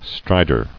[stri·dor]